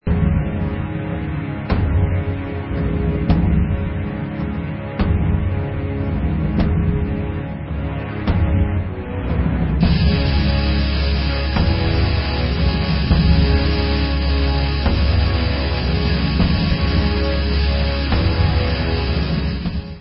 Extreme doom